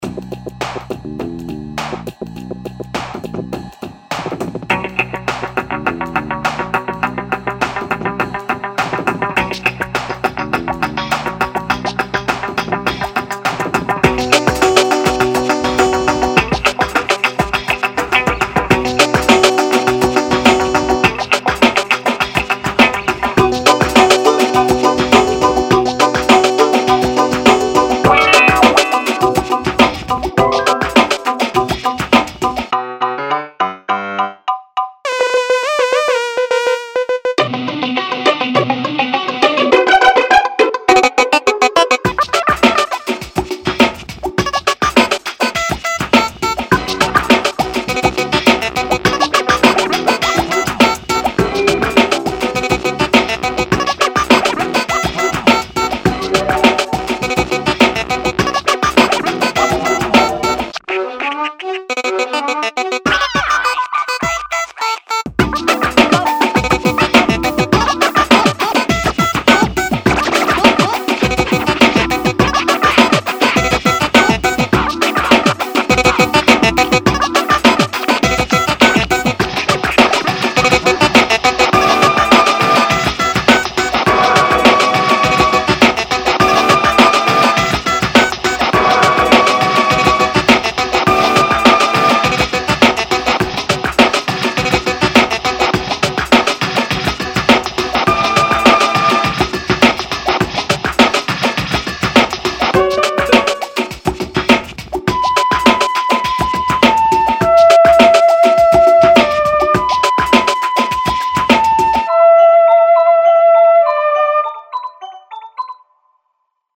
Так же планировался, как фоновая музыка в одном из мультиков.